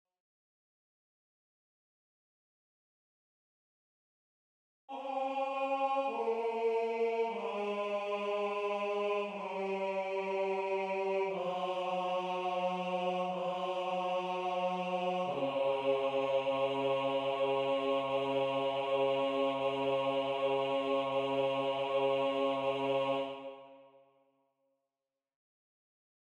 Key written in: C# Major
Type: Barbershop
Each recording below is single part only.